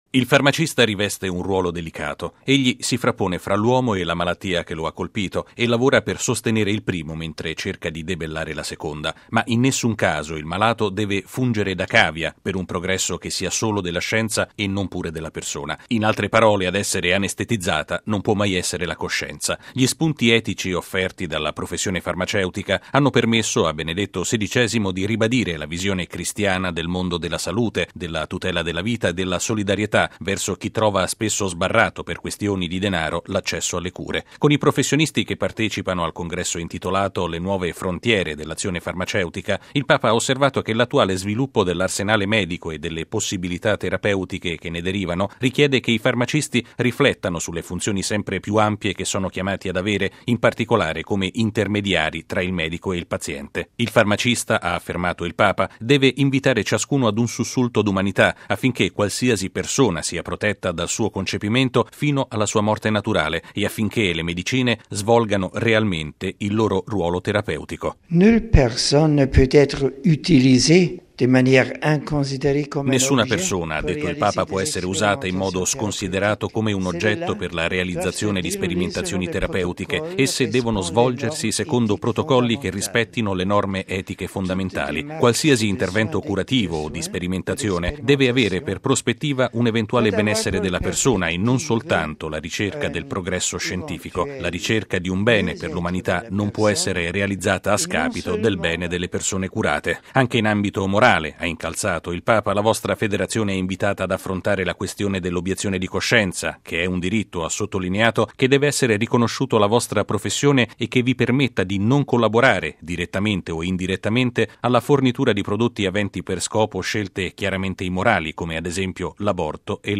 Sono alcuni dei punti che Benedetto XVI ha messo in evidenza nell'udienza di questa mattina ai farmacisti cattolici, impegnati in questi giorni a Roma nel loro 25.mo Congresso internazionale.